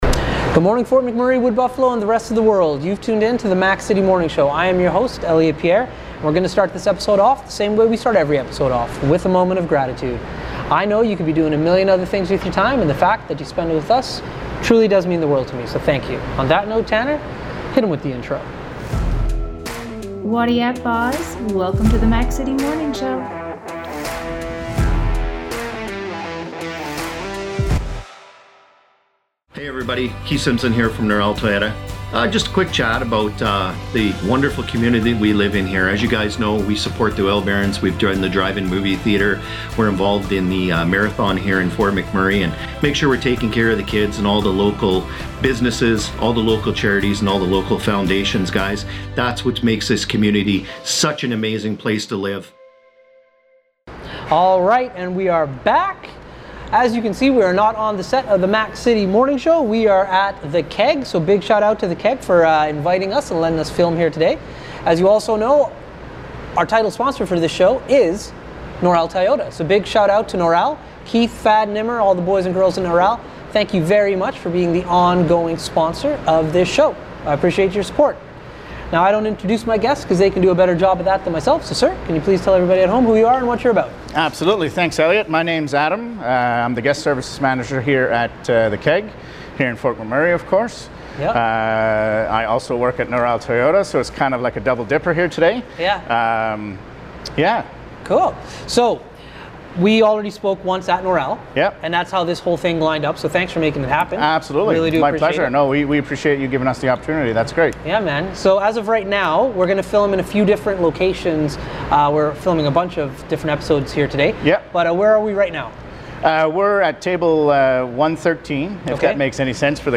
We are on location at the Keg